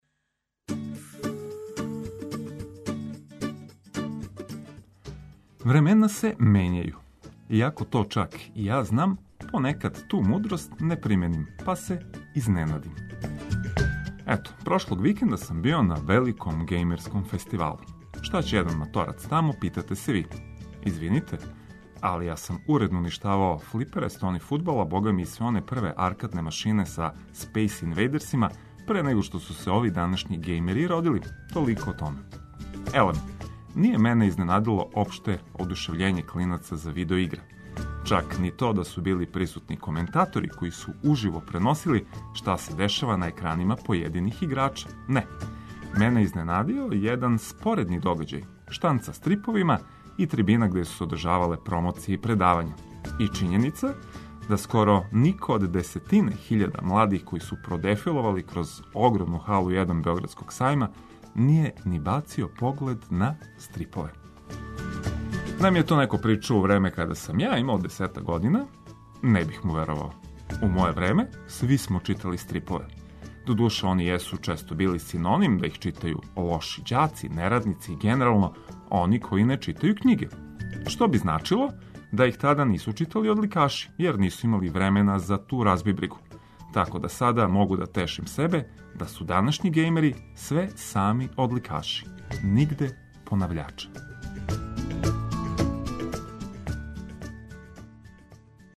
Буђење уз расположене радио пријатеље који знају све што је вама корисно.